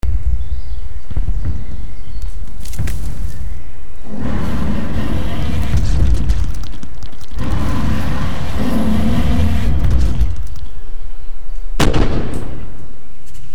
Mp3 Surround Effects by Mp3 Arena
Animal 423Kb  0:14 256 Sur Turn Up the Volume!
animal.mp3